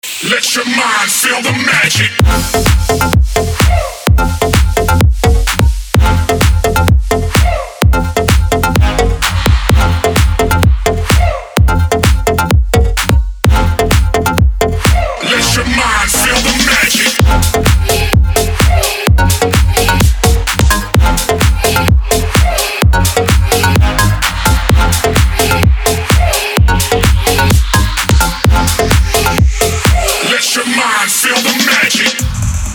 • Качество: 320, Stereo
electro